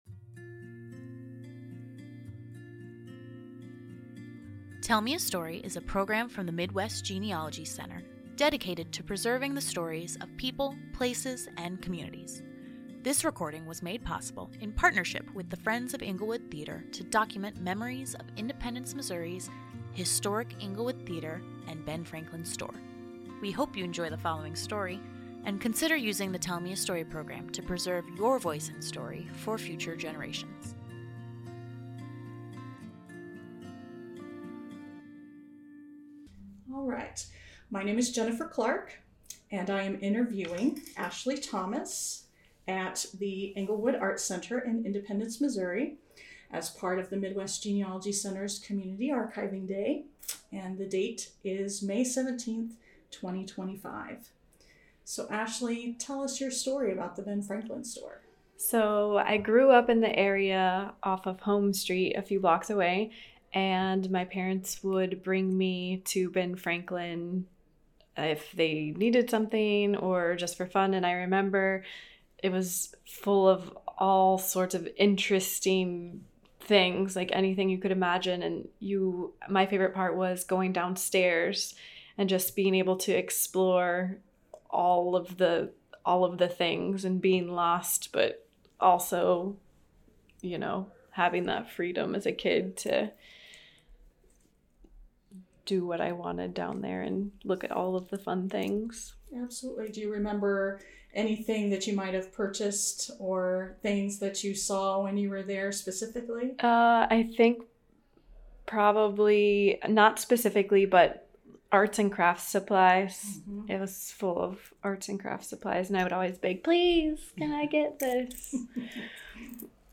Englewood Theater Community Archiving Day - Oral Histories
interviewer